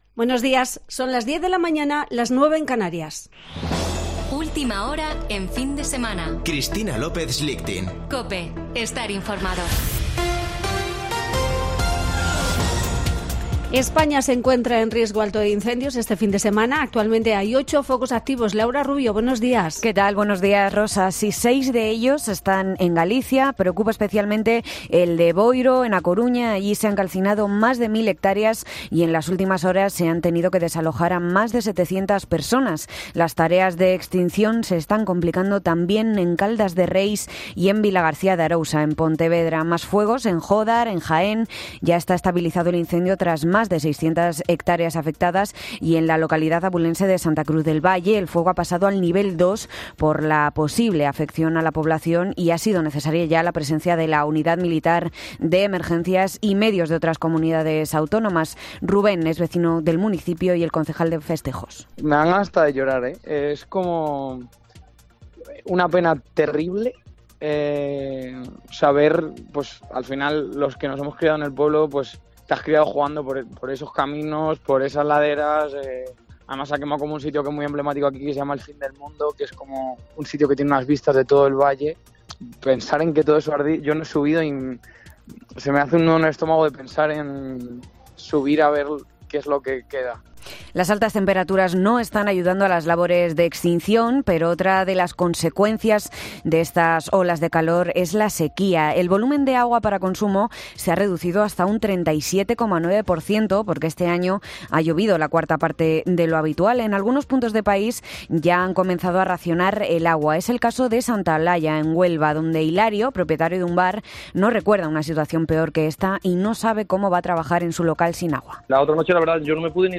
Boletín de noticias de COPE del 6 de agosto de 2022 a las 10.00 horas